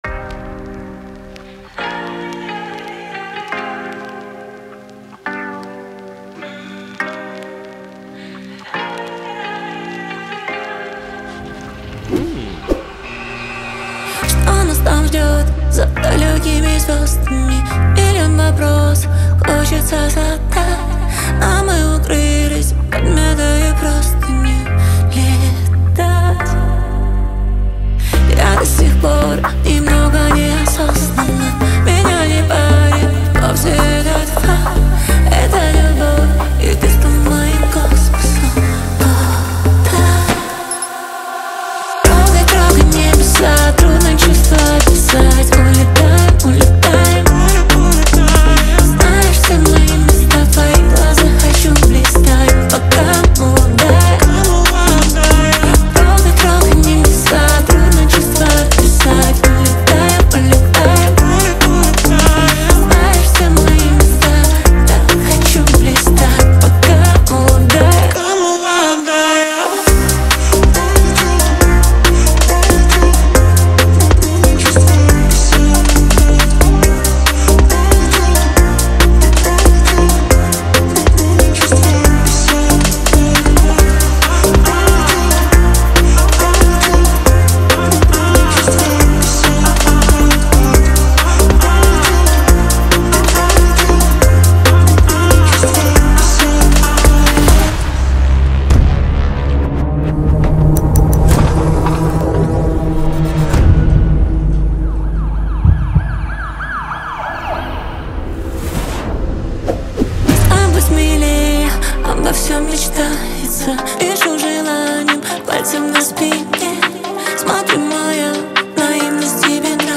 Жанр: Украинская